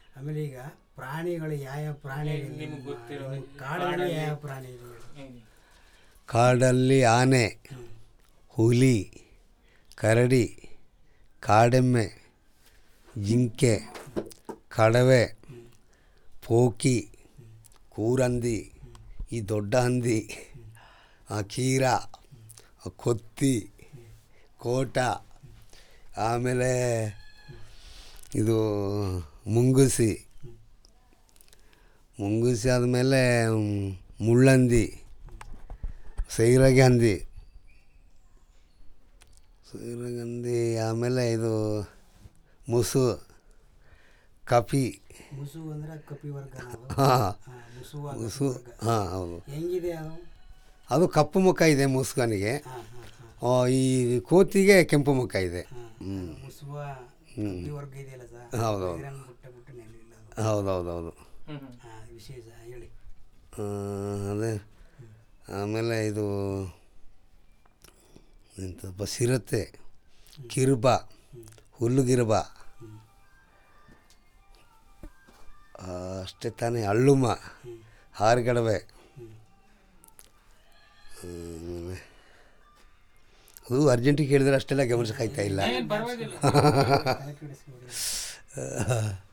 Elicitation of words about Wild Animals and related